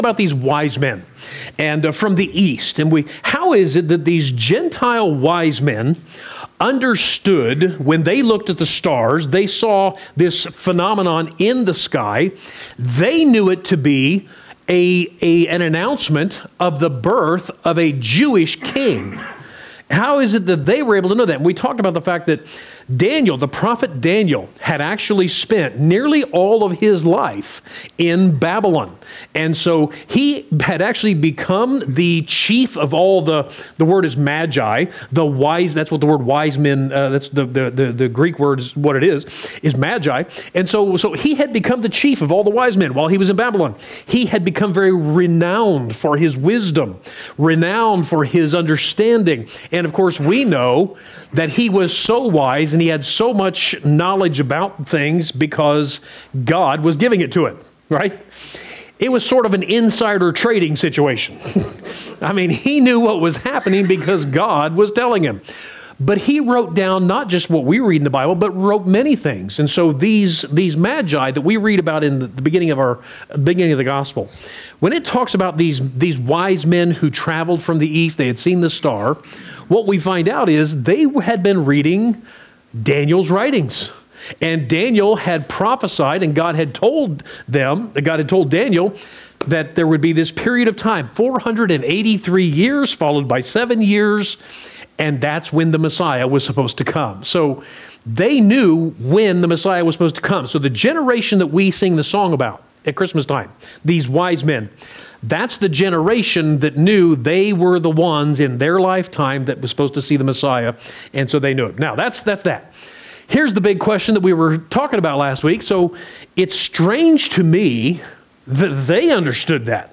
12-8-21 Wednesday Bible Study